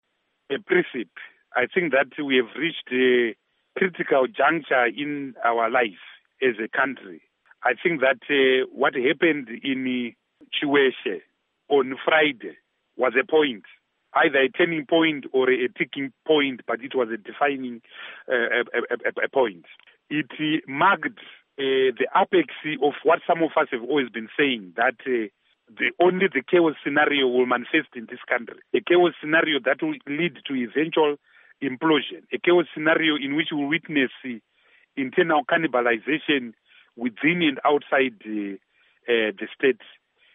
Zimbabwe opposition leader Tendai Biti of the People's Democratic Party says the recent rally addressed by the country's First Lady Grace Mugabe in Chiweshe, marked a crucial turning point for Zimbabwe and the ruling Zanu-PF party. Tune in for more of this interview at 7:30pm